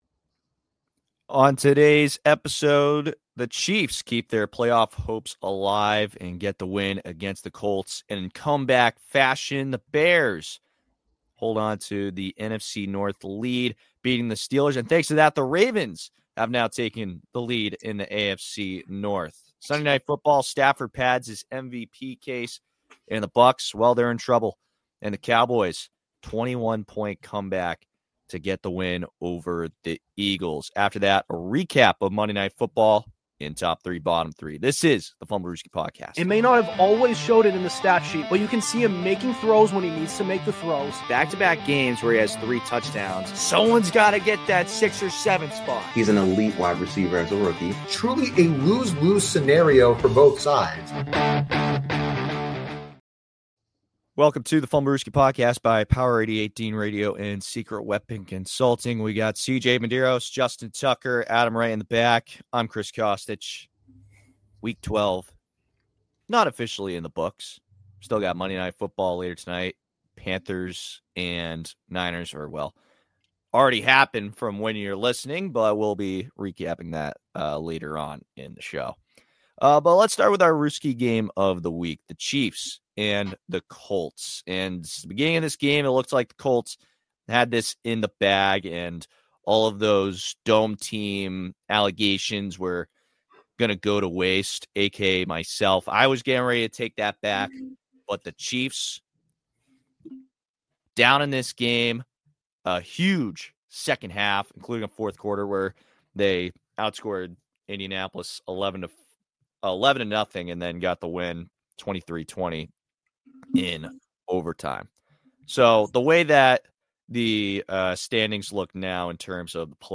An NFL podcast where we discuss all things pro-football-related, including recent news and hot takes. Hosted by four college guys